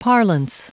Prononciation du mot parlance en anglais (fichier audio)
Prononciation du mot : parlance